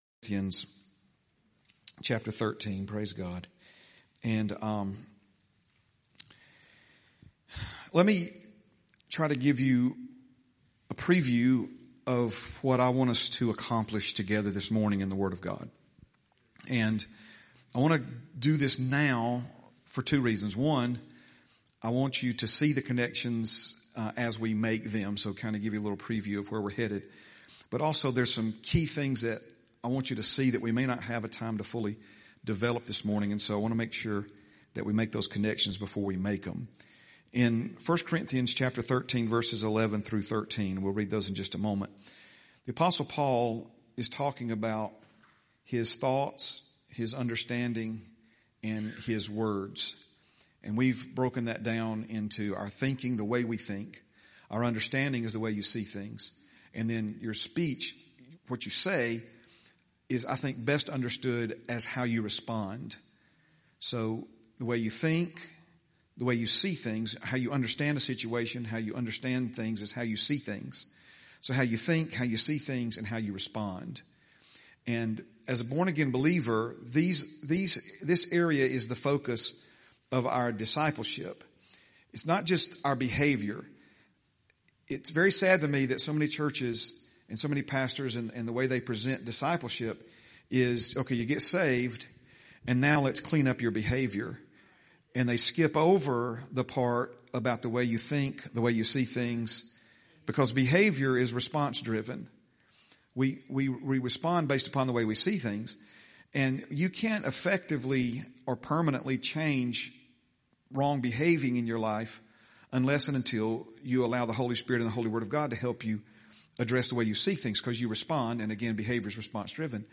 11/9/25 Sunday – Sunday Morning Message – Heritage Christian Center – Word of Faith Church Hueytown – Non-denominational